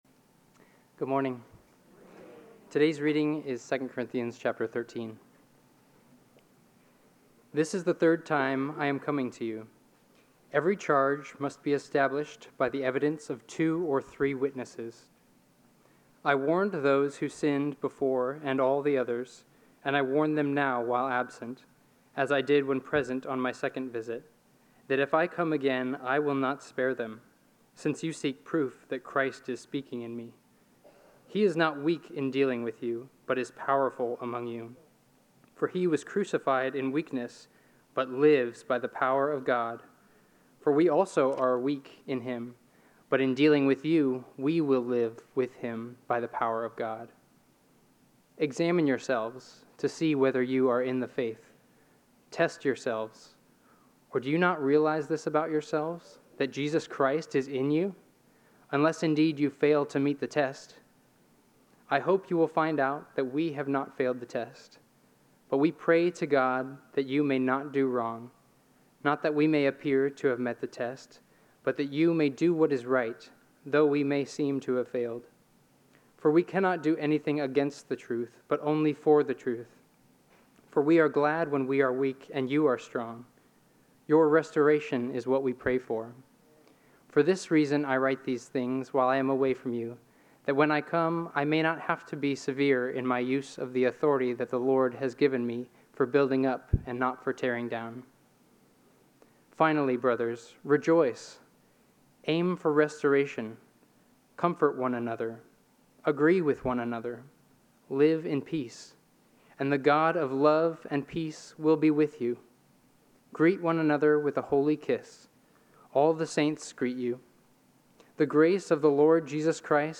Sermon Notes: Test Yourself